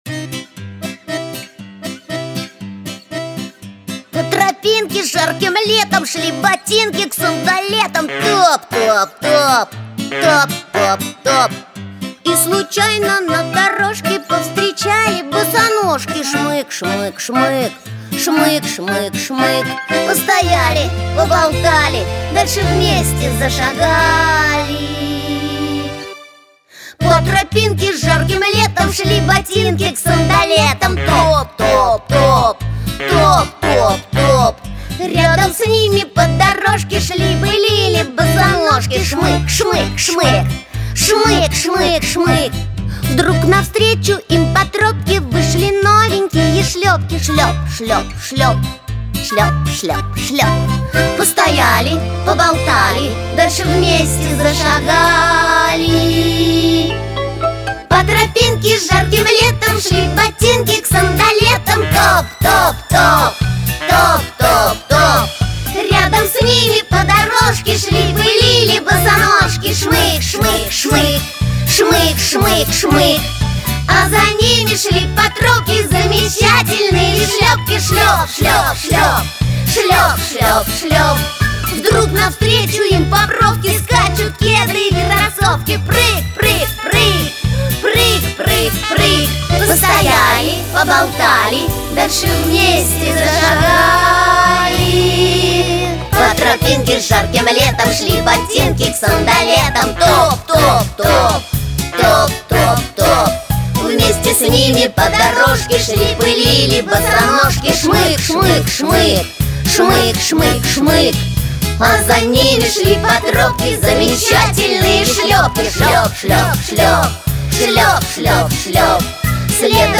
Aудиокнига По тропинке жарким летом Автор Сборник Читает аудиокнигу Андрей Усачев.